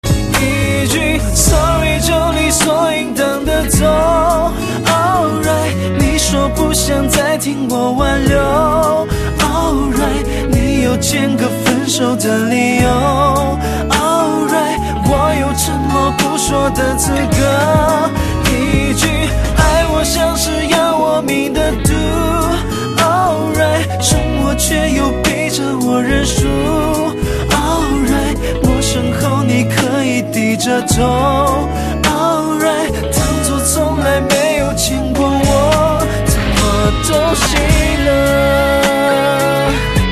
MP3铃声 大小